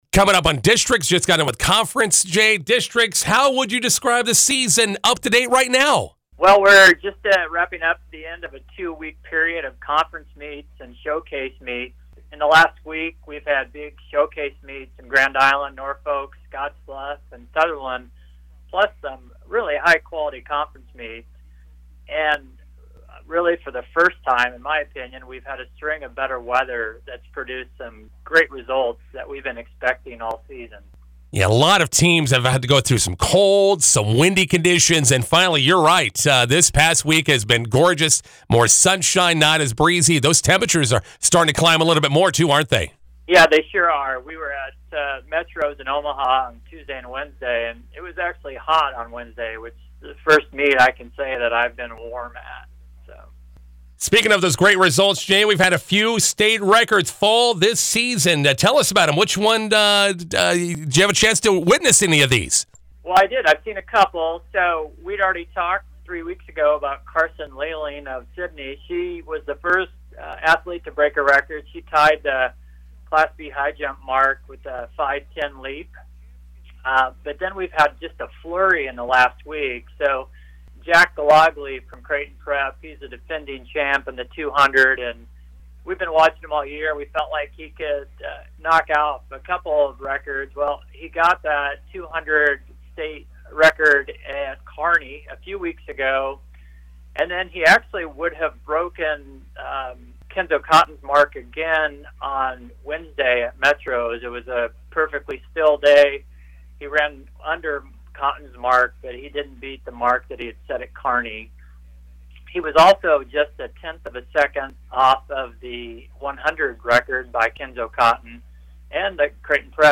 05/05/23 McCook radio interview